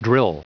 Prononciation du mot drill en anglais (fichier audio)
Prononciation du mot : drill